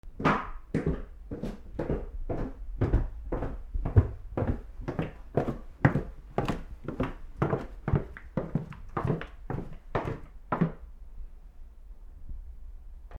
/ I｜フォーリー(足音) / I-190 ｜足音 階段
階段を降りる 少し早め(ぞうり)
階段下からマイク固定で録音